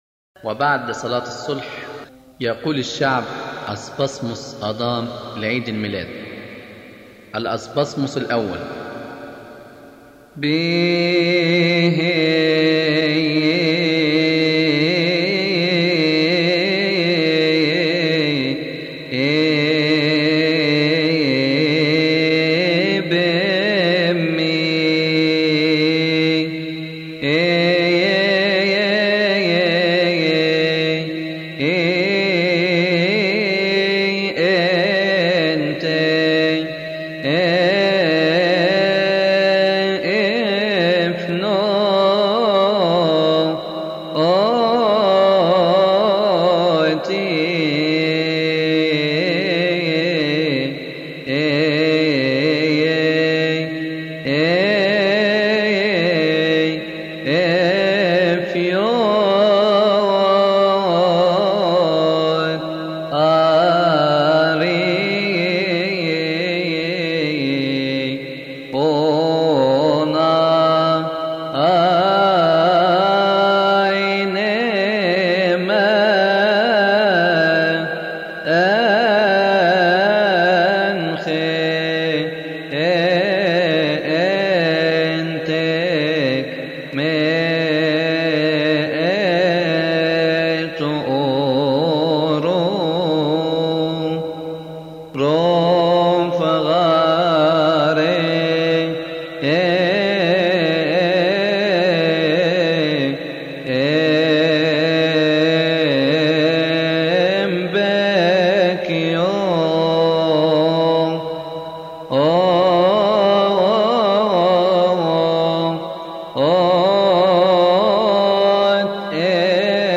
لحن: أسبازموس آدام أول لعيد الميلاد